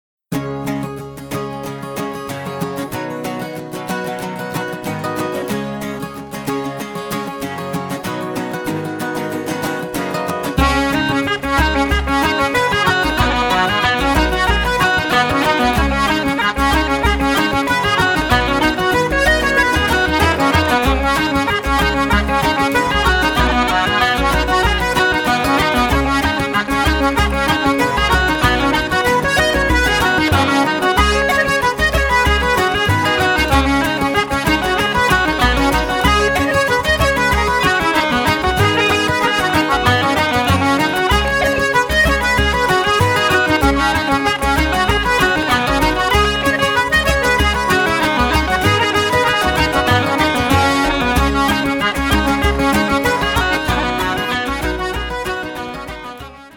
Backing Vocals
Bodhrán
Piano
the album is a collection of 13 eclectic songs and tunes.